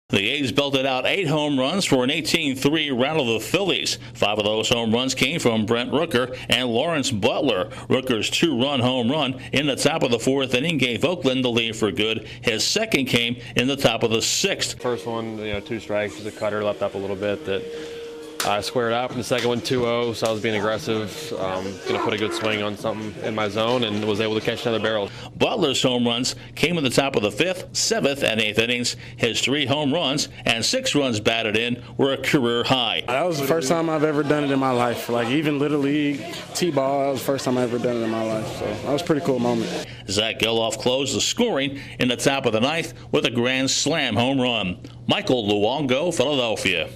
The Athletics are homer happy in a crushing of the Phillies. Correspondent